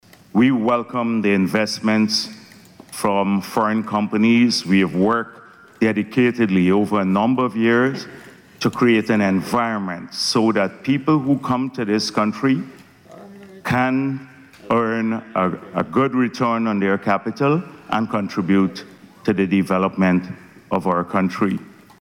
Day Two of the Energy Conference saw Vice President Dr. Bharrat Jagdeo reaffirming Guyana’s commitment to ensuring the safety of investments in its burgeoning oil and gas industry.